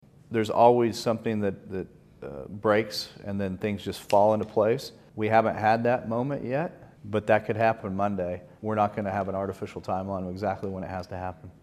CLICK HERE to listen to commentary from Senate President Pro-Tem Greg Treat.